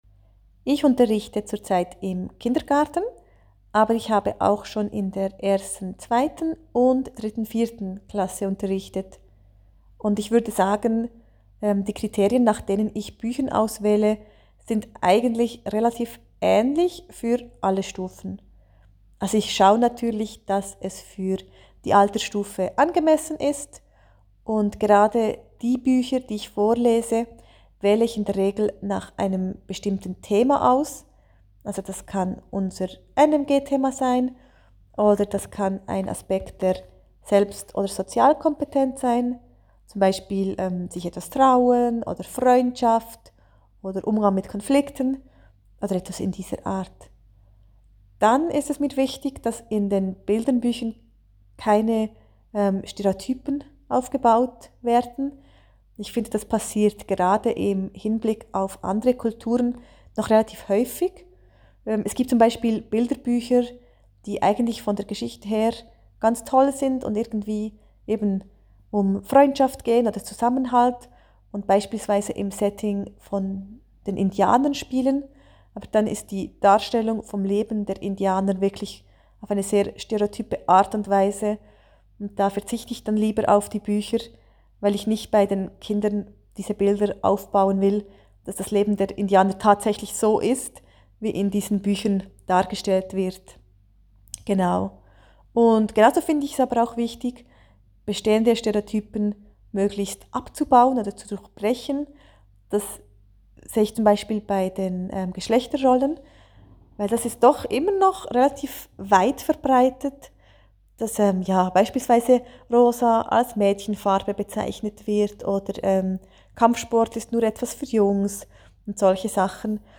Hier hören Sie von zwei Lehrpersonen, was ihnen bei der Bücherauswahl wichtig ist: